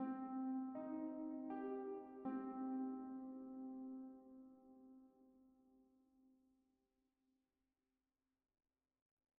bass_maia_arpeggio.wav